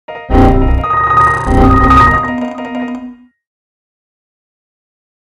На этой странице собрана коллекция звуков цифровых глюков, сбоев и помех.
Звук глюка Windows открывший множество окон и зависший